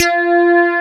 BSLEADA4.wav